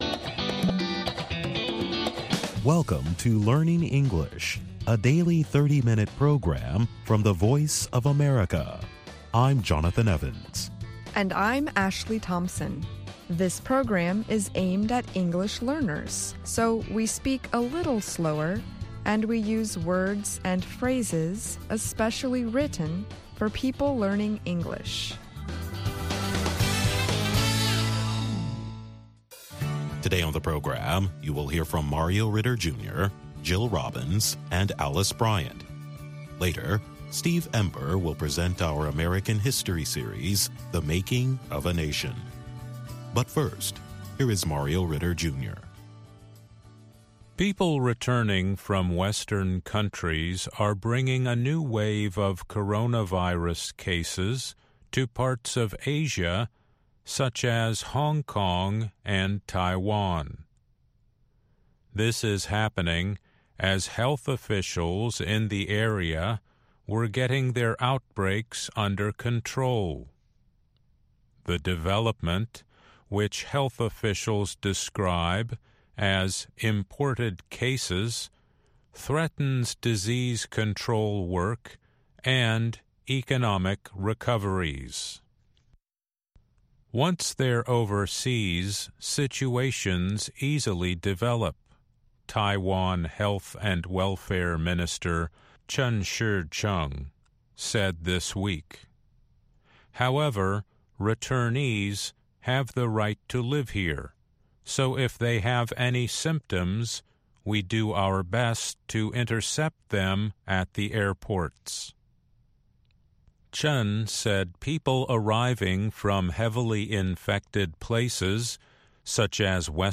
Learning English uses a limited vocabulary and is read at a slower pace than VOA's other English broadcasts.